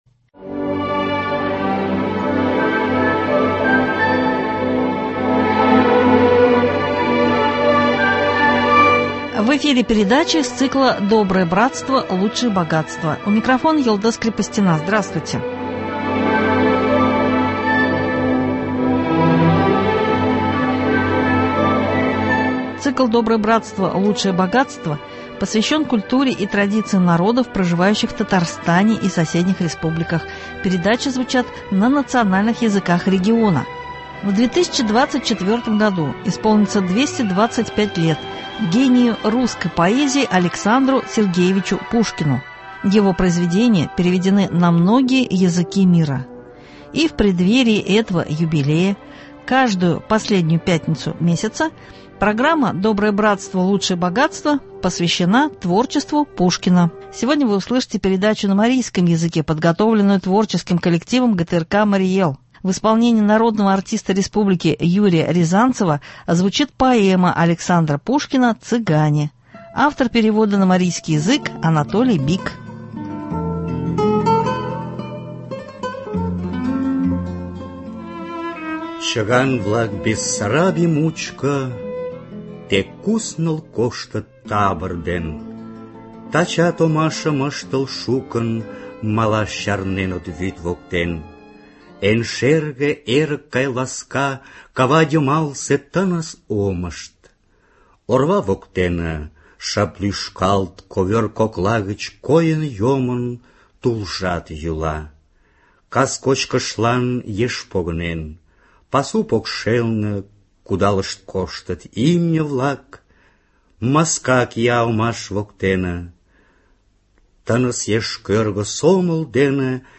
Сегодня вы услышите передачу на марийском языке, подготовленную творческим коллективом ГТРК Марий Эл.